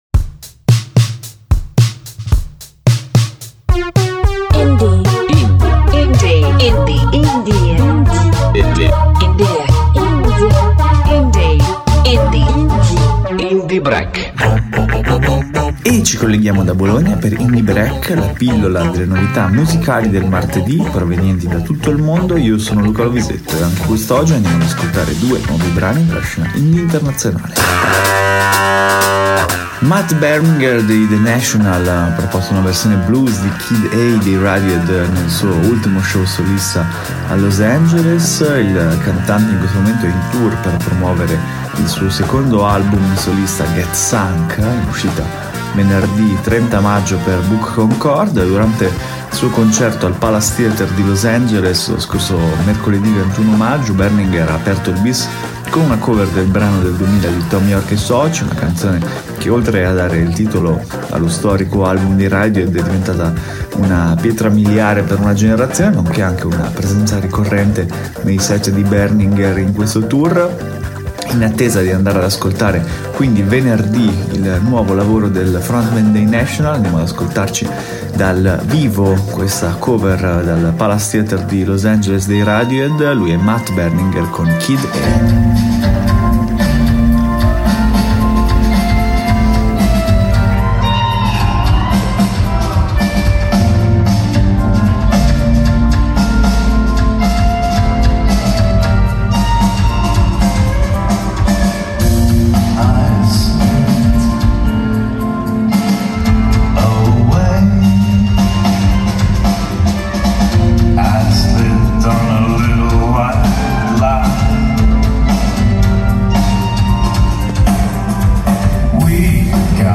si è esibita dal vivo